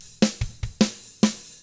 146ROCK F1-L.wav